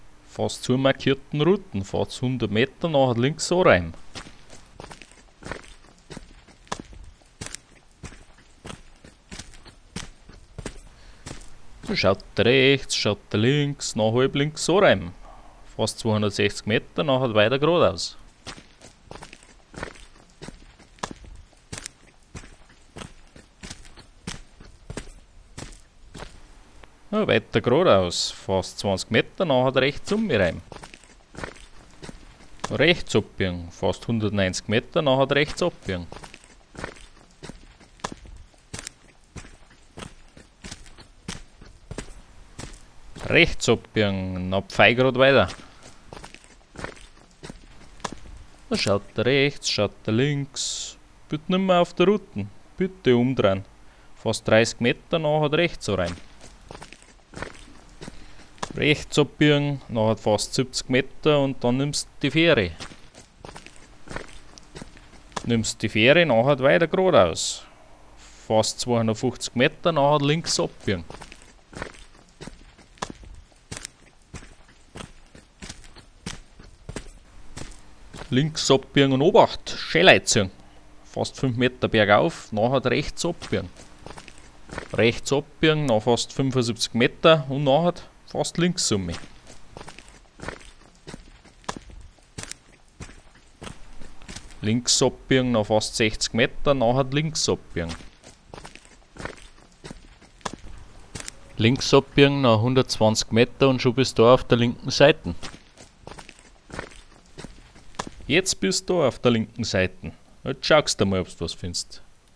Naviaufzeichnung: